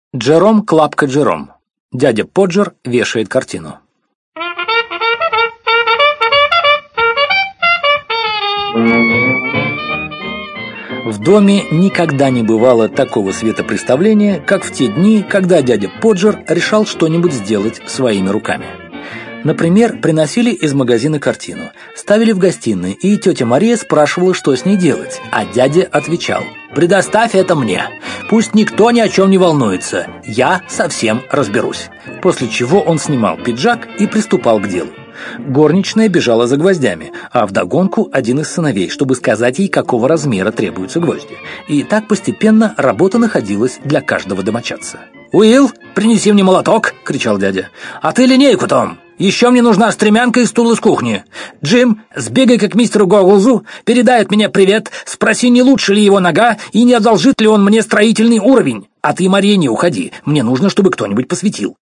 Аудиокнига Рассказы с юмором | Библиотека аудиокниг